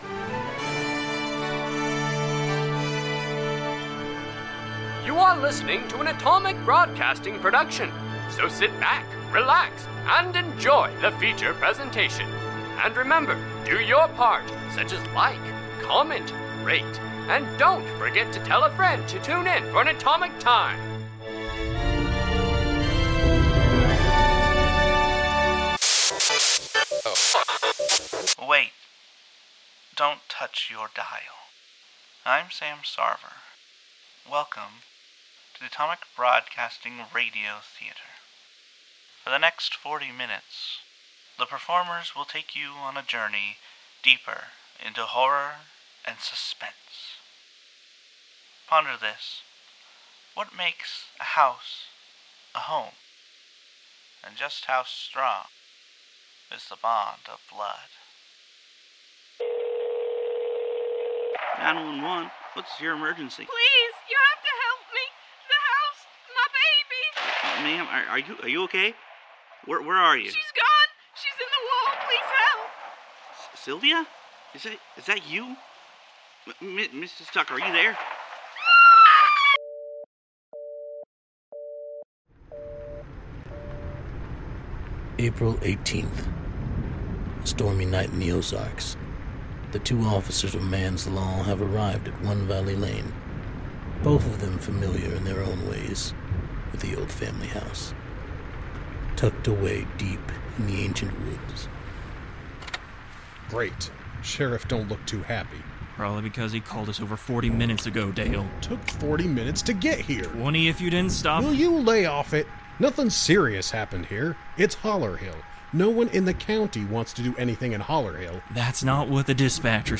This is a Horror show involving Mature Themes: Viewer Discretion is advised Deep in the Ozarks, Two officers are called in to investigate a potential kidnapping at 1 Valley Lane, when they enter, nothing is the same.